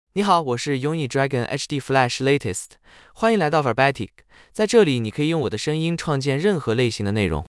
Yunyi Dragon HDFlash Latest is a male AI voice for Chinese (Mandarin, Simplified).
Voice sample
Listen to Yunyi Dragon HDFlash Latest's male Chinese voice.
Male